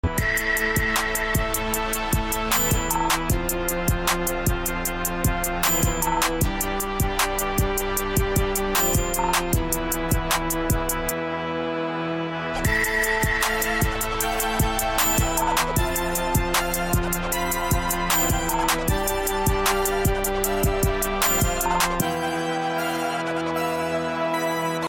Making beats from scratch with the MPC in the studio this is part 2